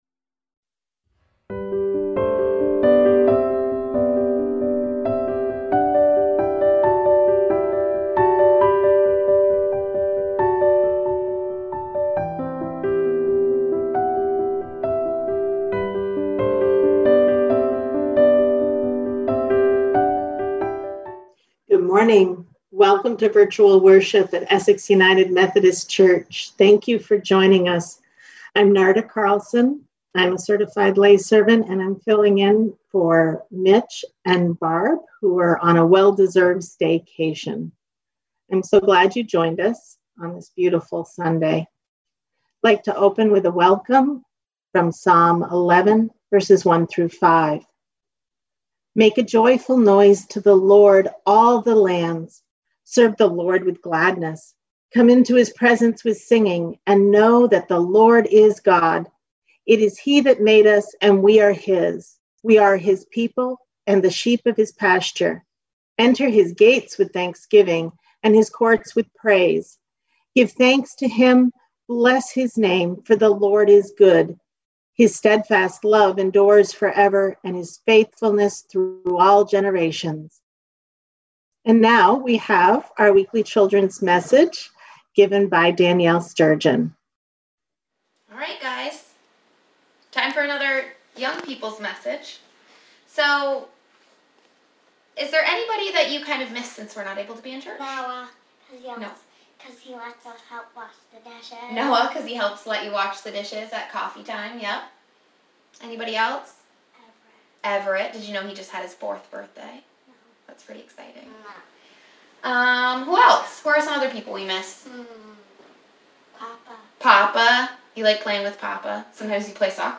We held virtual worship on Sunday, August 16, 2020 at 10am.